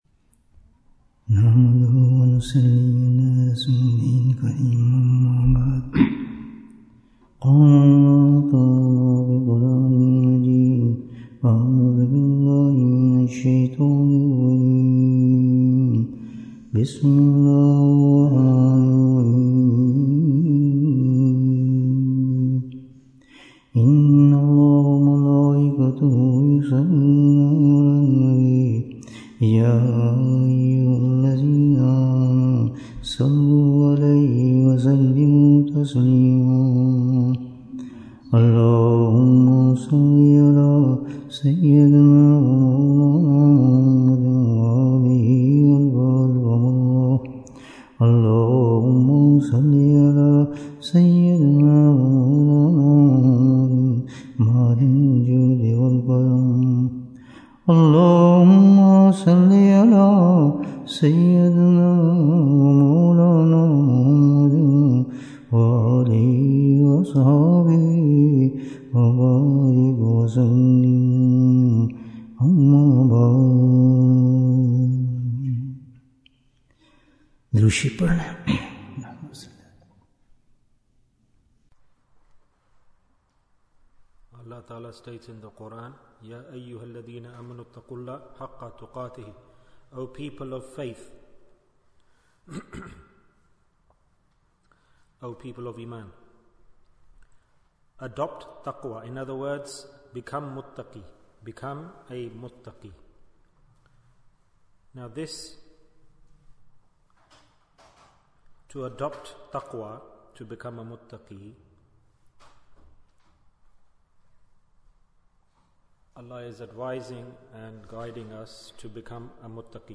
The Second Rule of Naqshbandi Silsila Bayan, 55 minutes19th September, 2024